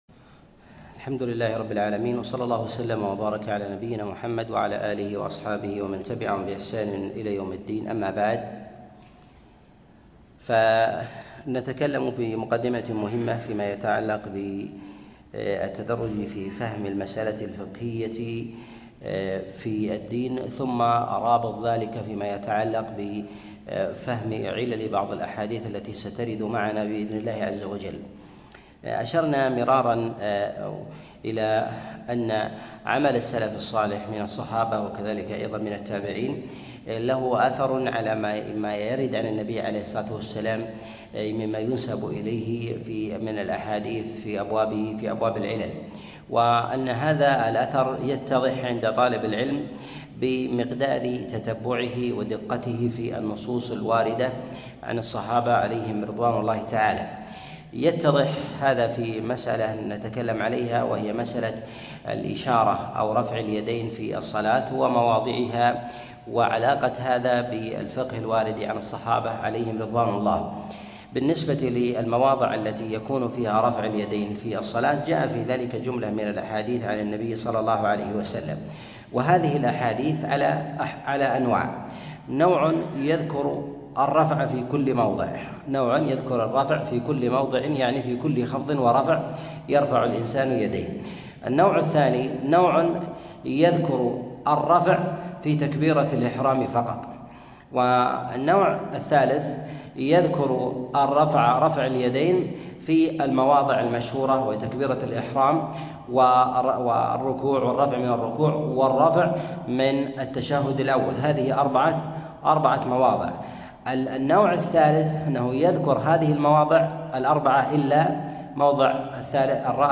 الأحاديث المعلة في الصلاة الدرس 35